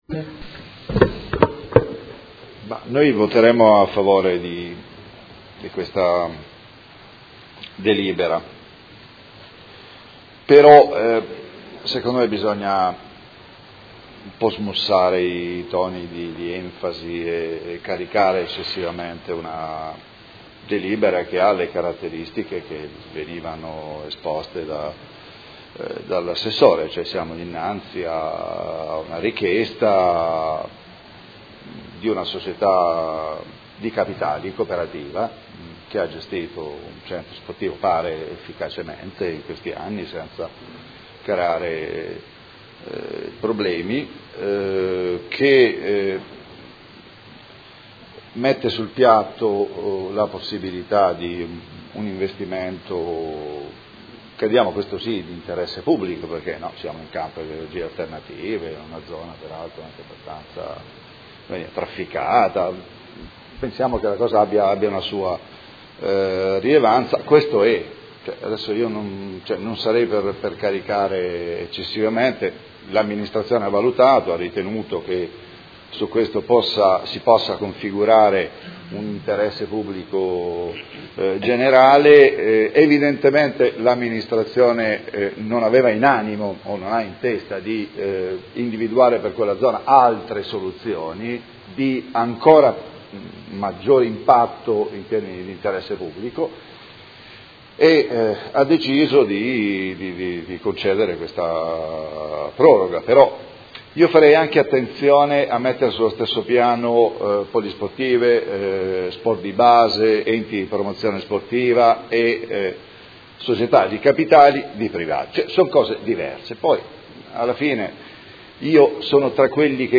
Seduta del 20/07/2017 Dichiarazione di voto.
Audio Consiglio Comunale